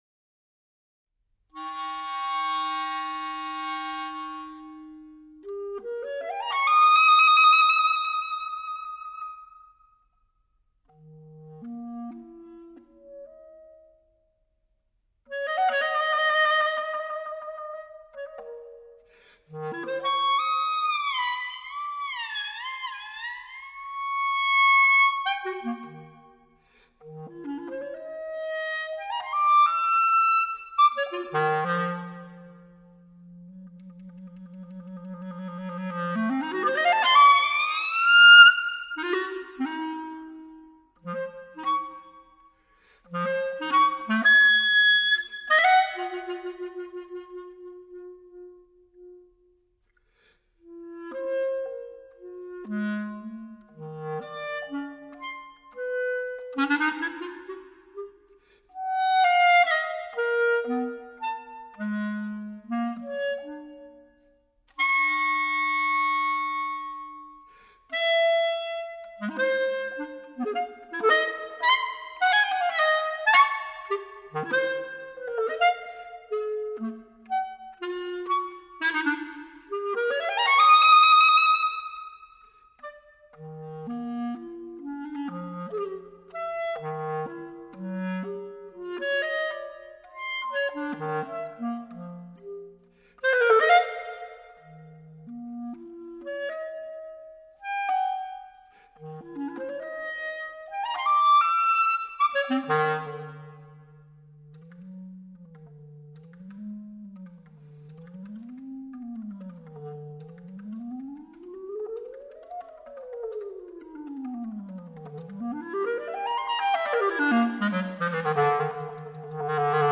Modern / Clarinet Solo.